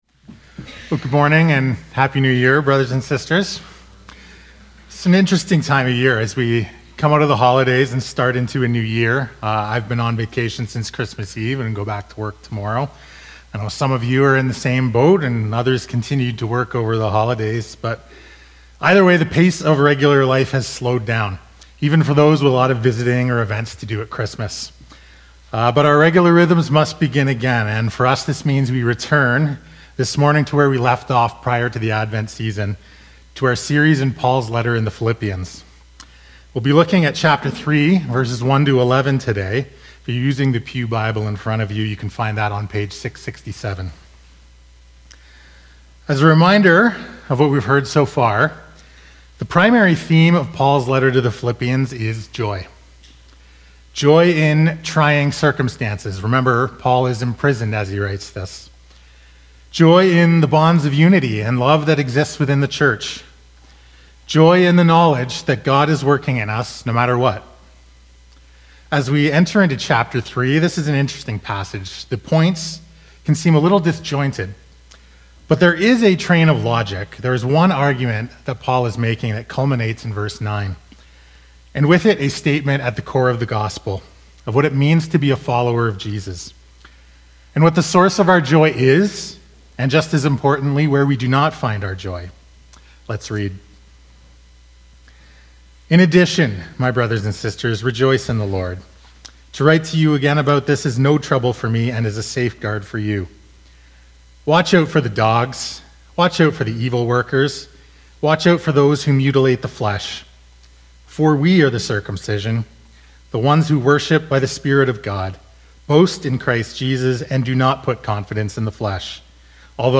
Sunday morning sermons from Jacqueline Street Alliance Church.